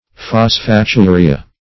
Search Result for " phosphaturia" : The Collaborative International Dictionary of English v.0.48: Phosphaturia \Phos`pha*tu"ri*a\, n. [NL.